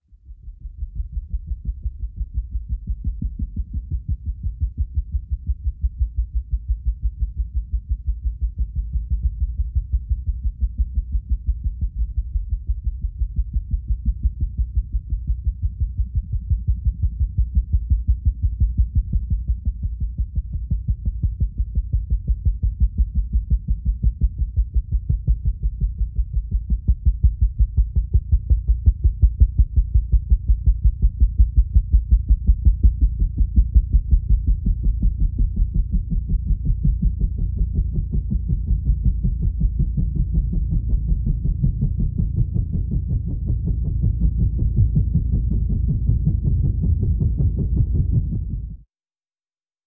Звуки замедленного времени
Звук лопастей вертолета в замедленном воздухе